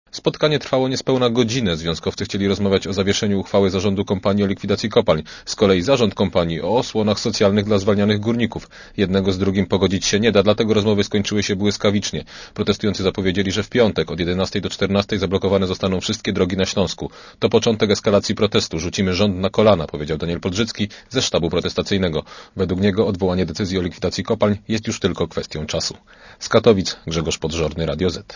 Komentarz audio (112Kb)